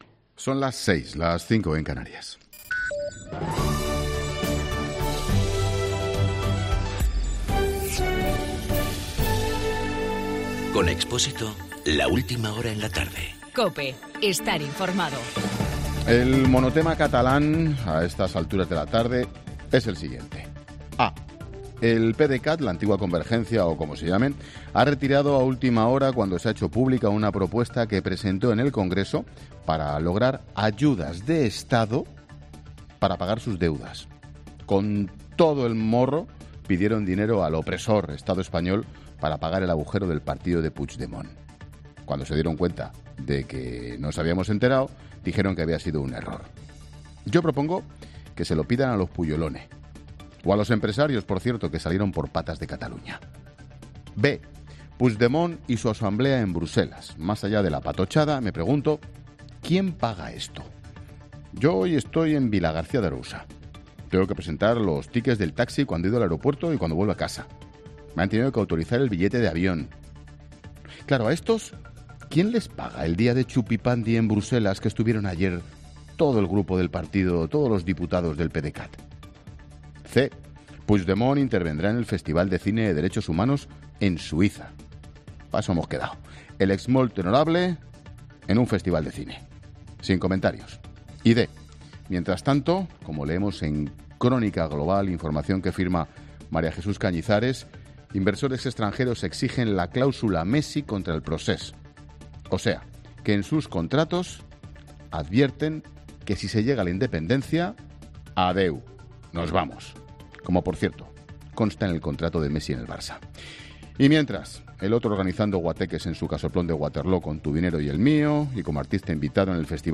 Monólogo de Expósito
Comentario de Ángel Expósito sobre la situación de Cataluña.